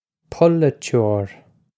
polaiteoir puh-luh-chore
This is an approximate phonetic pronunciation of the phrase.